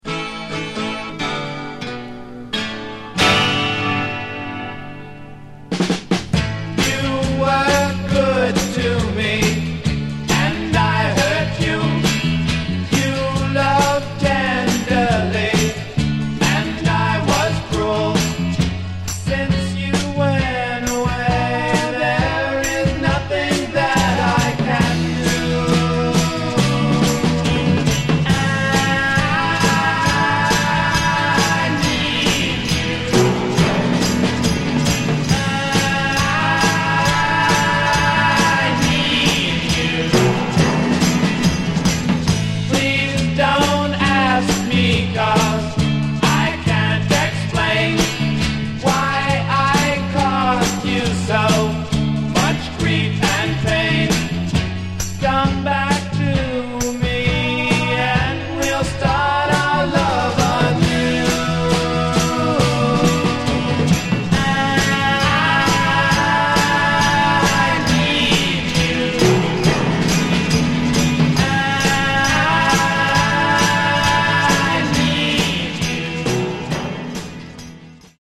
Genre: Power Pop
This rare single hosts two great Beatlesque tracks.